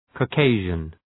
Προφορά
{kɔ:’keıʒən}
Caucasian.mp3